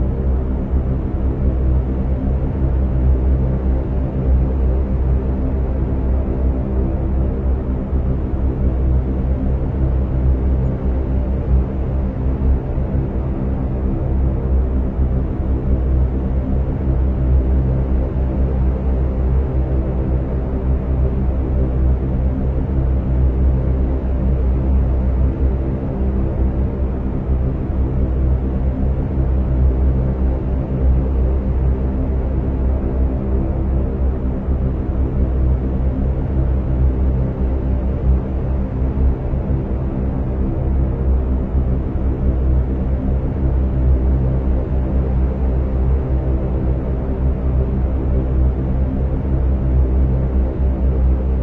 航空旅游/航空/航空业 " 巡航20013英尺
描述：21,000英尺，4个引擎，戴上氧气罩。巡航。